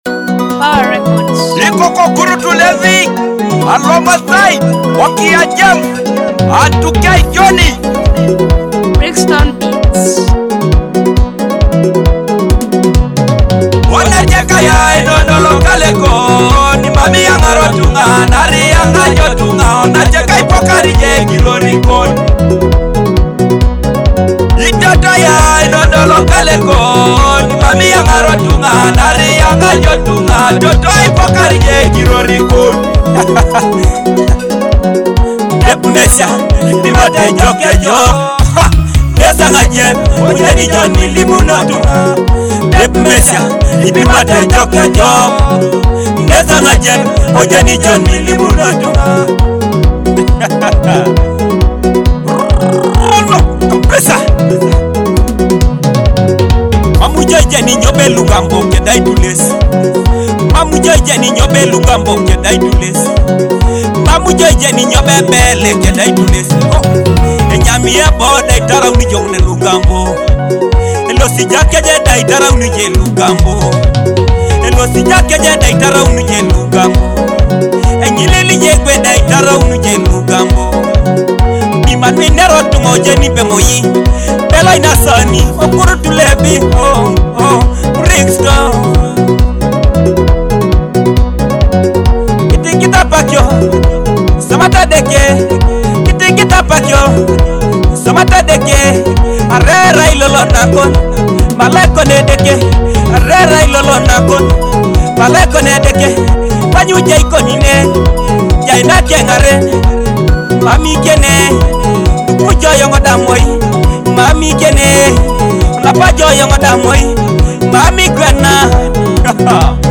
blends traditional rhythms with contemporary Afrobeat